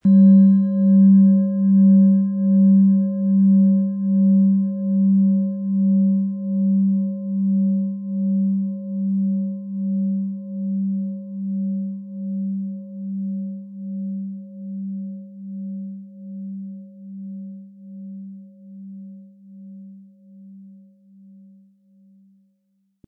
Sie sehen und hören eine von Hand gefertigt Tageston Klangschale.
• Mittlerer Ton: Mars
Unter dem Artikel-Bild finden Sie den Original-Klang dieser Schale im Audio-Player - Jetzt reinhören.
MaterialBronze